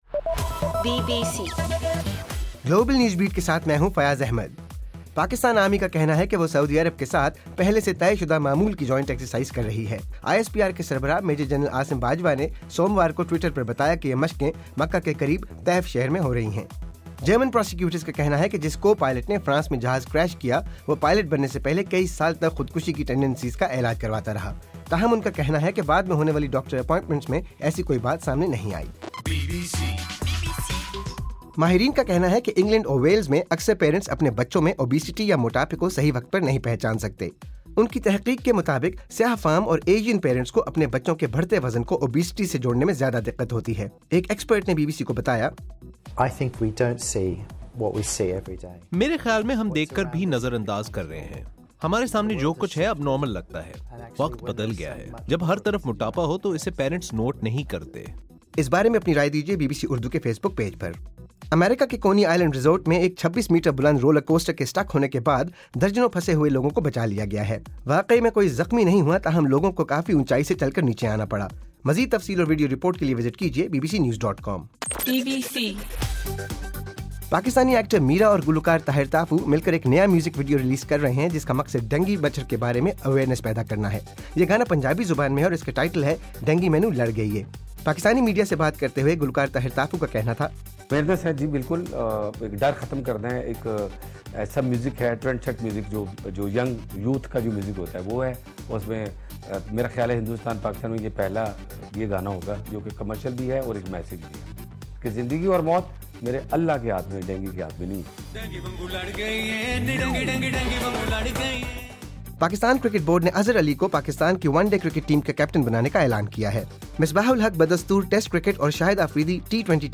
مارچ 30: رات 12 بجے کا گلوبل نیوز بیٹ بُلیٹن